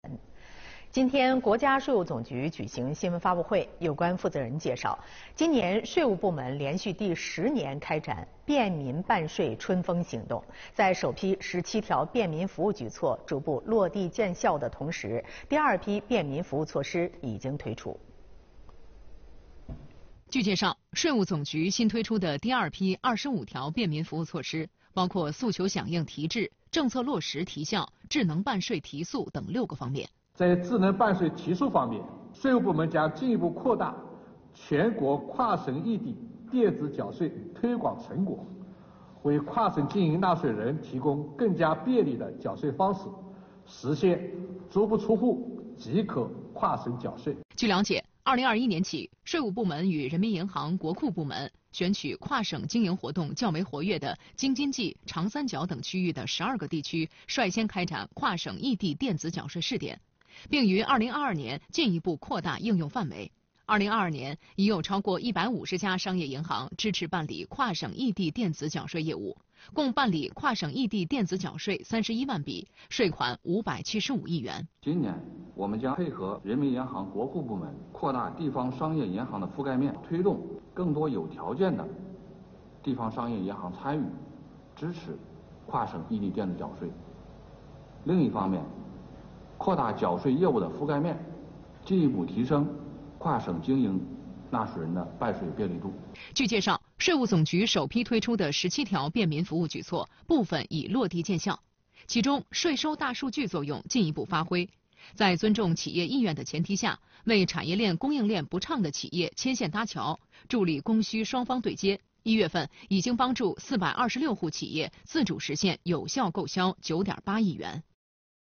2月21日，国家税务总局举行新闻发布会，有关负责人介绍，今年税务部门连续第10年开展“便民办税春风行动”，在首批17条便民服务举措逐步落地见效的同时，第二批便民服务措施已经推出。